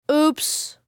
На этой странице собраны звуки «упс» — весёлые и искренние реакции на мелкие промахи.